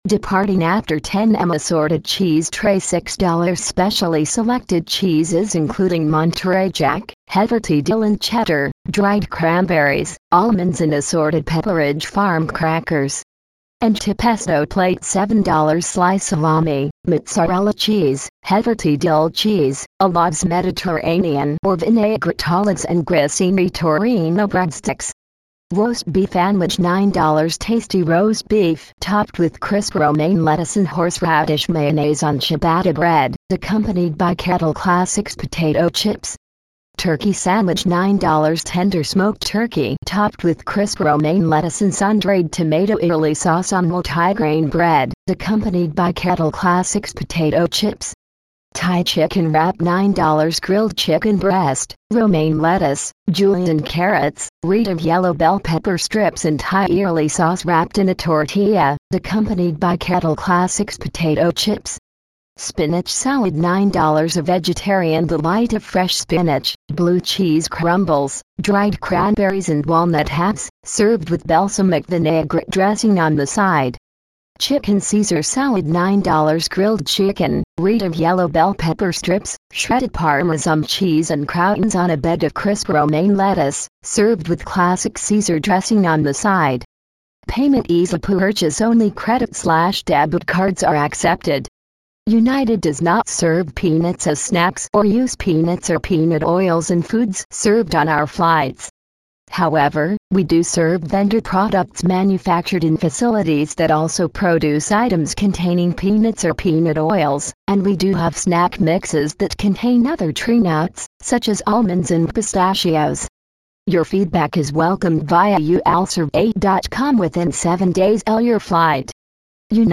IntelReader_Demonstration_ReadingAloud.mp3